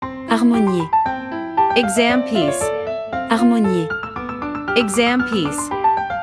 Professional-level Piano Exam Practice Materials.
• Vocal metronome and beats counting
• Master performance examples